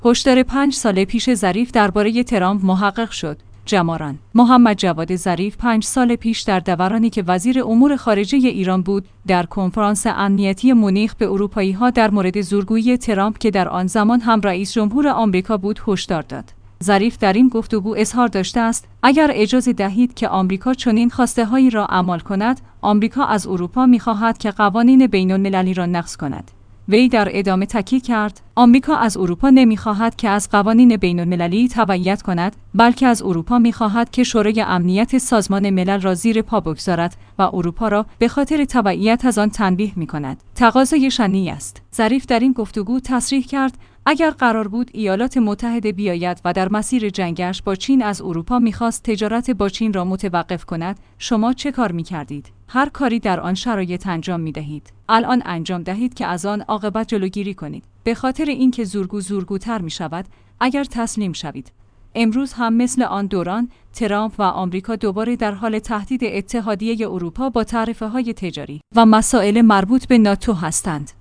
جماران/ محمدجواد ظریف پنج سال پیش در دورانی که وزیر امور خارجه ایران بود در کنفرانس امنیتی مونیخ به اروپایی ها در مورد زورگویی ترامپ که در آن زمان هم رئیس جمهور آمریکا بود هشدار داد.